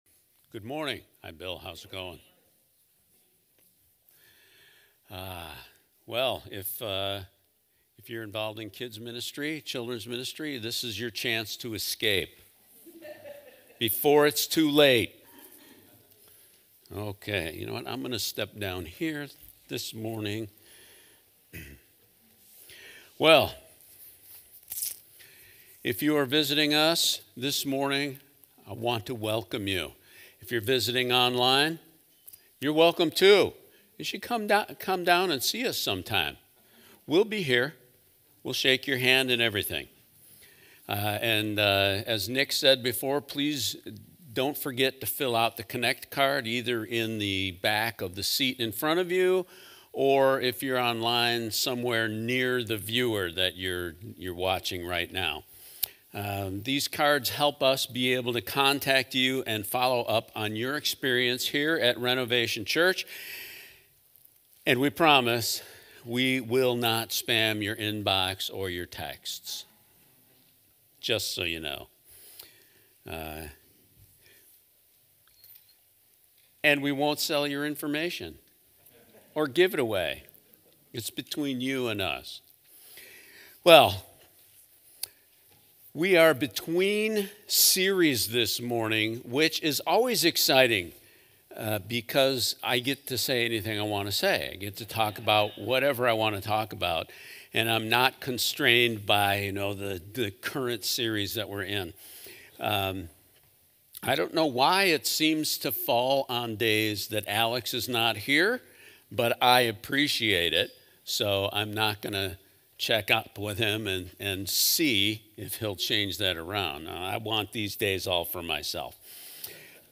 The implications for guiding and leading our families is huge as we follow Christ. This sermon explores multiple ways of what it means to shepherd your family, who gets to shepherd the family, and how to do it in the right way.